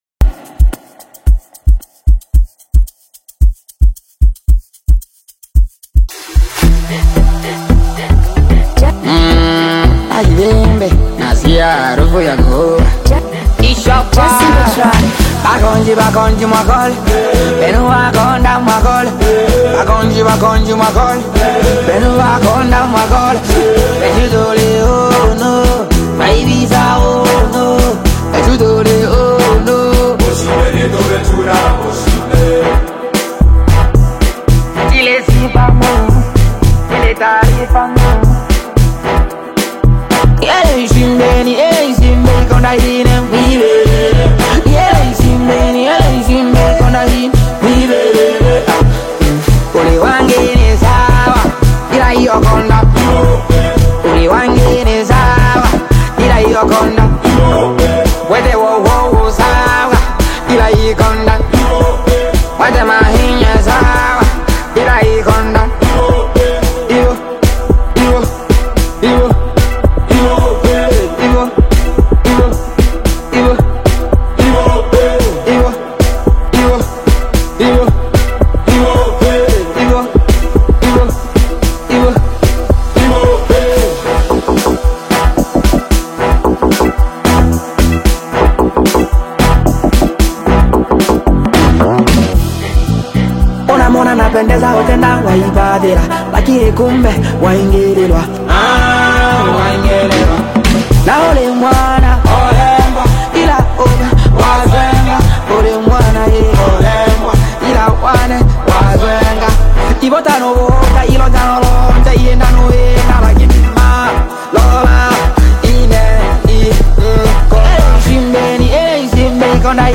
AmapianoAudio
is a lively Tanzanian Afro-Fusion/Bongo Flava single